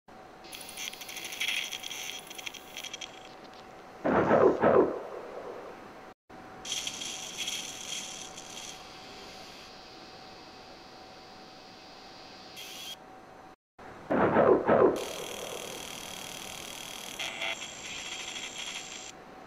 Their mechanical "barking" is so good
Generation Zero Runner Sounds (Calm).mp3 (audio/mpeg)